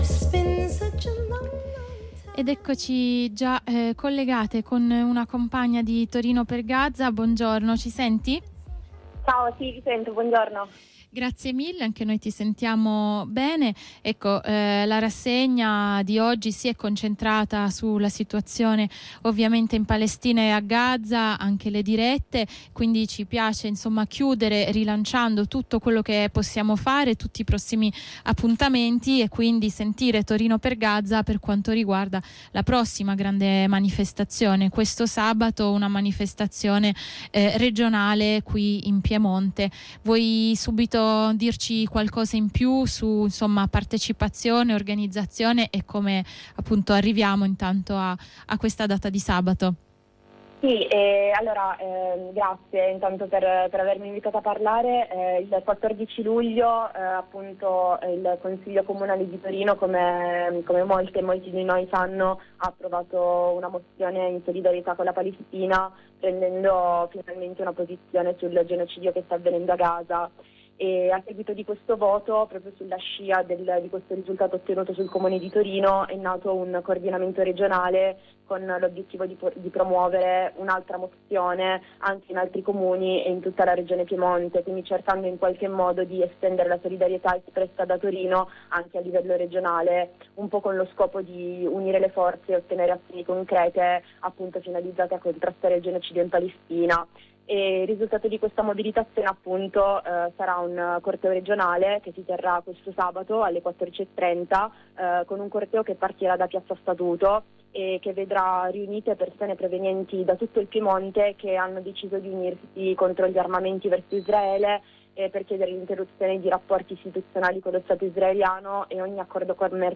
Ci dice qualcosa in più su questo corteo (con concentramento in piazza statuto alle 14:30) una compagna di Torino per Gaza.